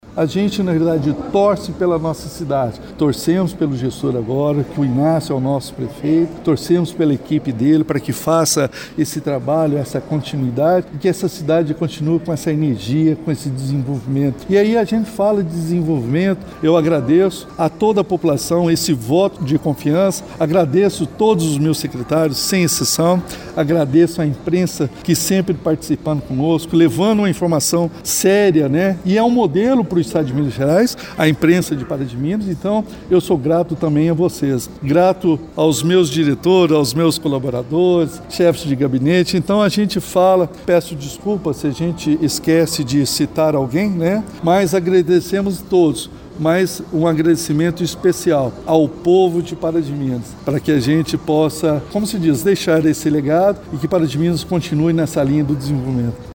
Em coletiva de imprensa, no Teatro Municipal, o Prefeito Elias Diniz fez uma apresentação das principais ações da administração municipal em 2024.